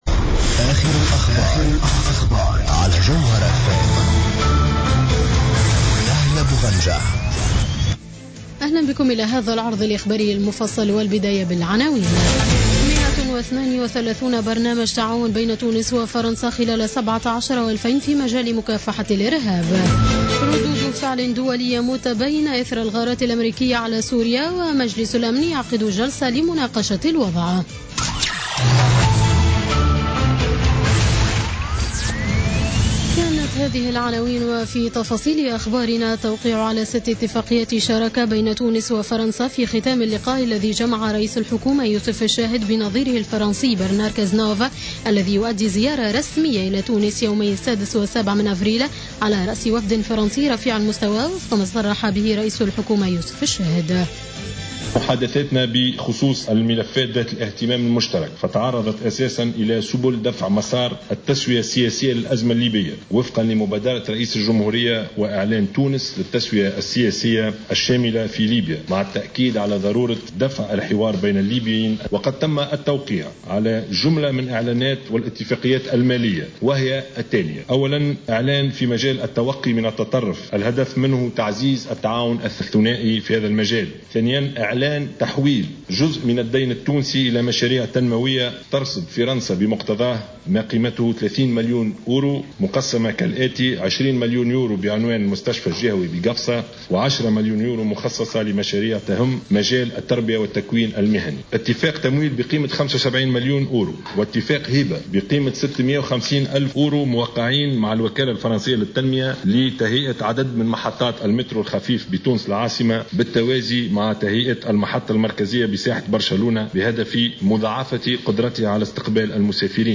نشرة أخبار السابعة مساء ليوم الجمعة 7 أفريل 2017